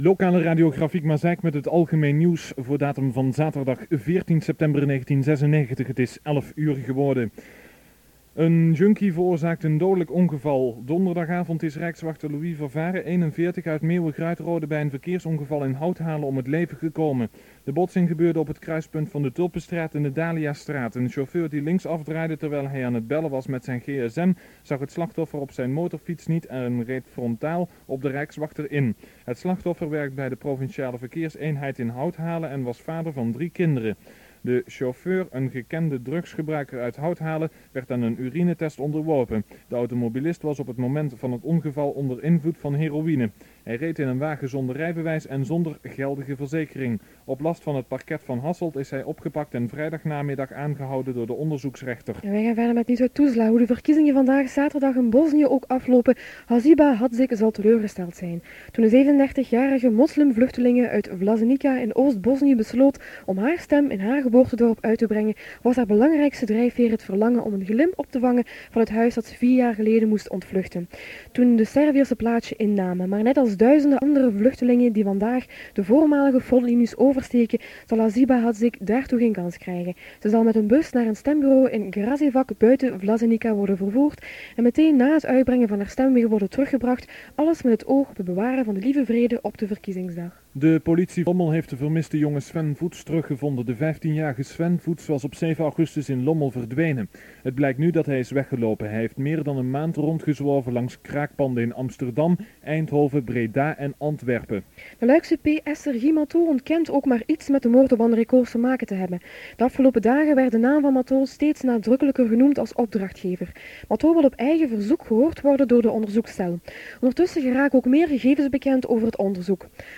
The texts (the actual news) came from a commercial news service provider through Videotex and were printed before the volunteer read out the news.
Nieuwsbulletin.mp3